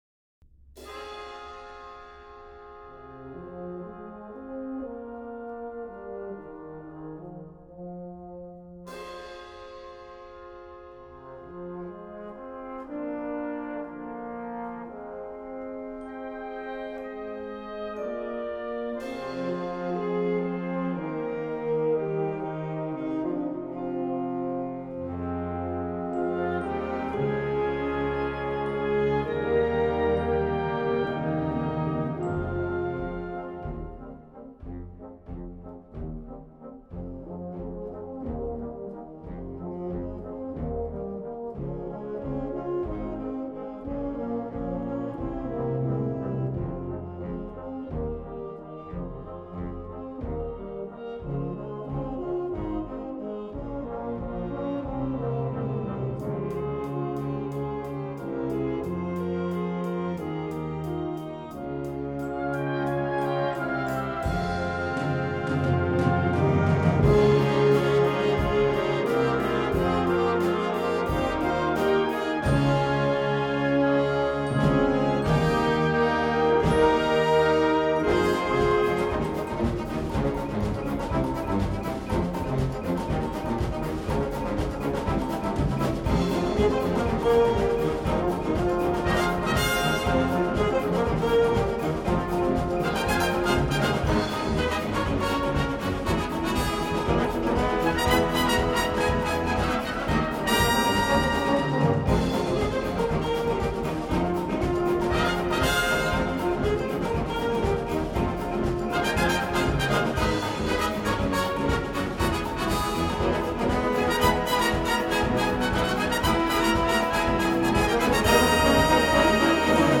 A4 Besetzung: Blasorchester PDF